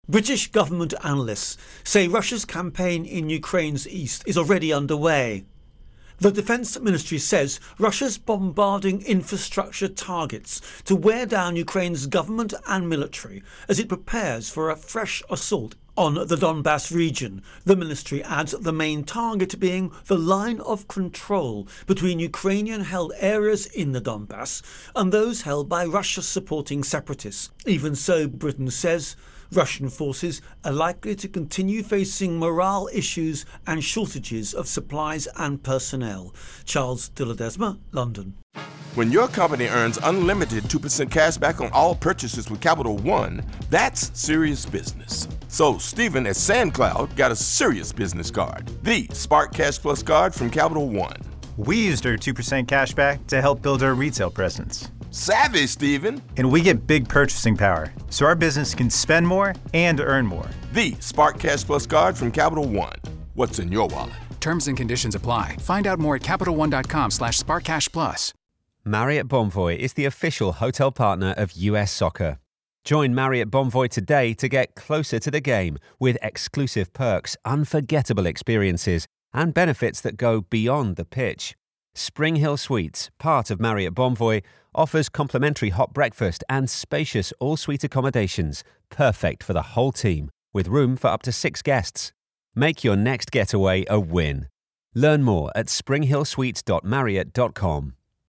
Russia Ukraine War Donbass Intro and voicer